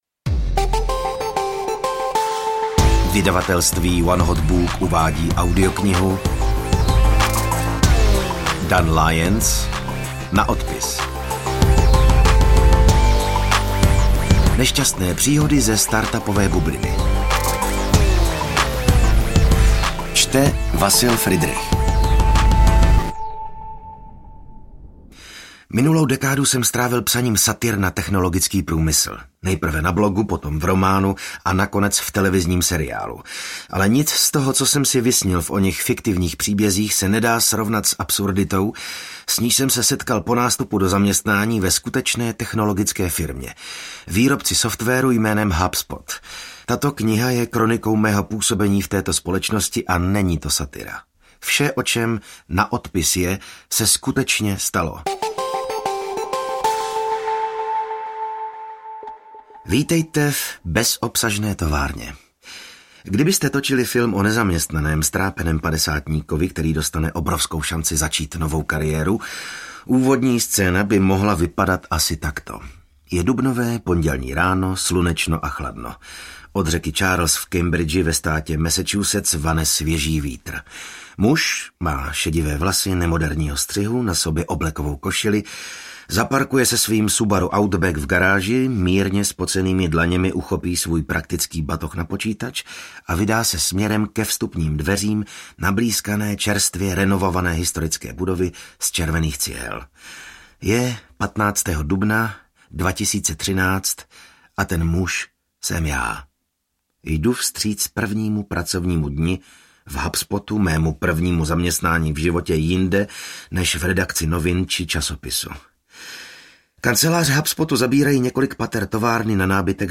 Na odpis audiokniha
Ukázka z knihy